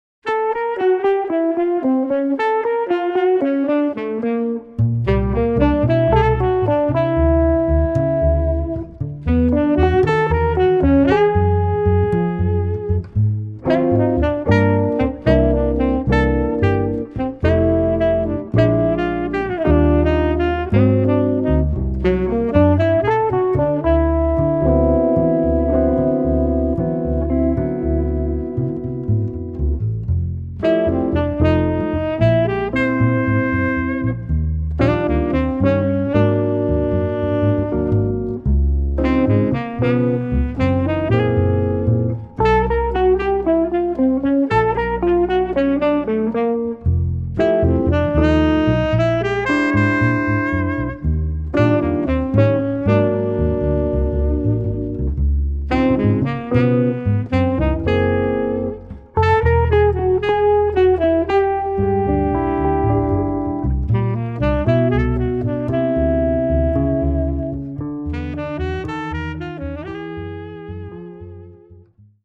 Even 8ths (medium)